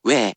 We’re going to show you the character, then you you can click the play button to hear QUIZBO™ sound it out for you.
In romaji, 「ゑ」 is transliterated as 「we」which sounds sort of like 「way」, but can sometimes be written as 「うぇ